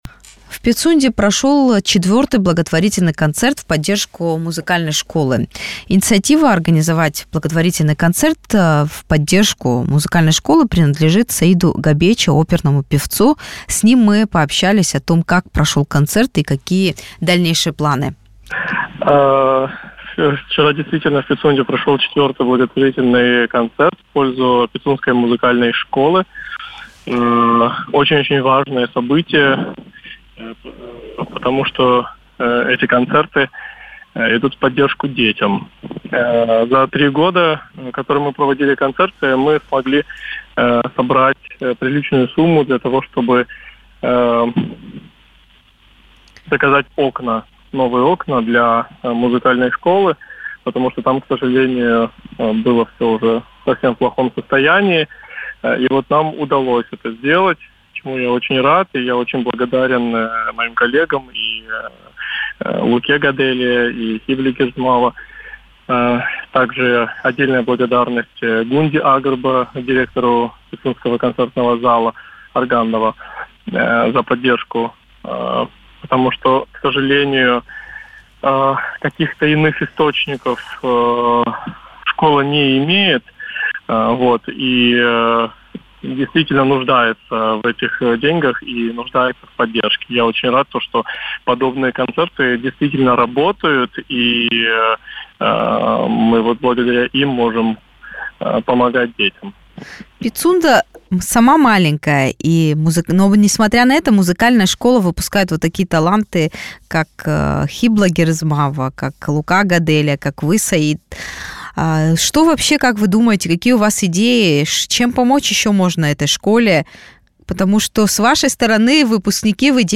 Актуальный комментарий